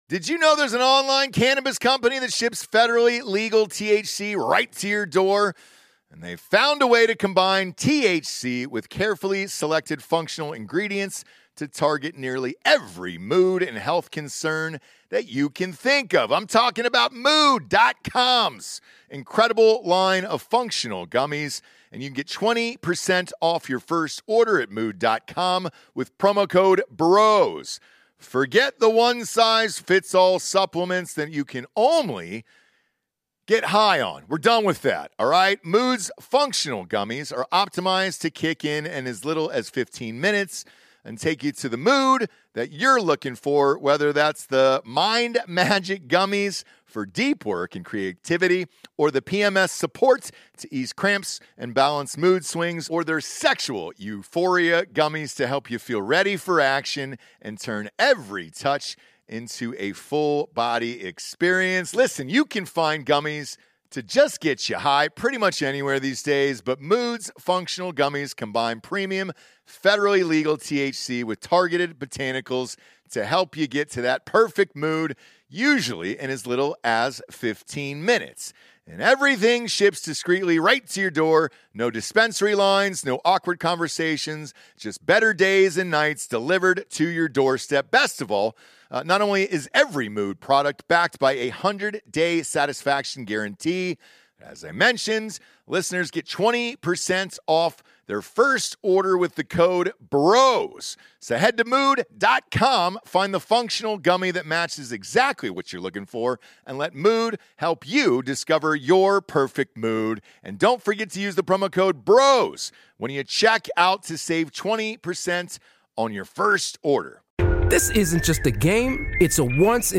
Fake News 117 - Special Guest Breaking Points Host Saagar Enjeti